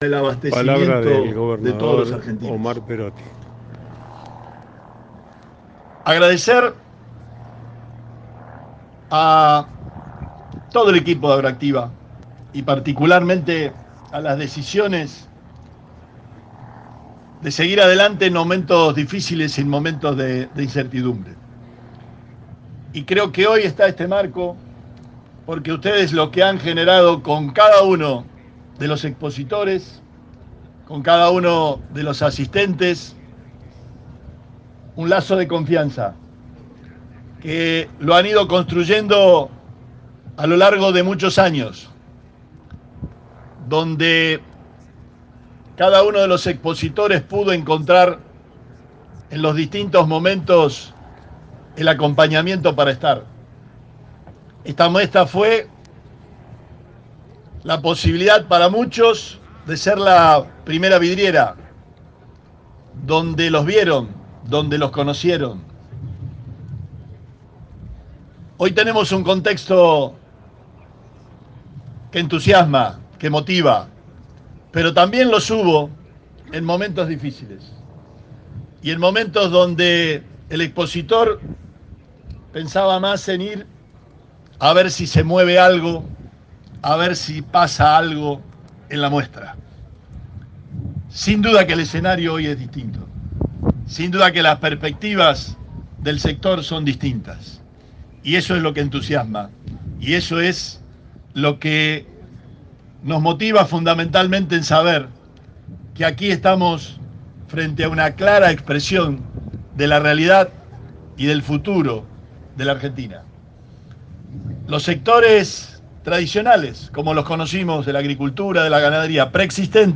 La inauguración de la 28 edición de AgroActiva convocó emociones, recuerdos, abrazos, encuentros y reencuentros frente al pórtico de entrada de la megamuestra.
Omar Perotti – gobernador de Santa Fe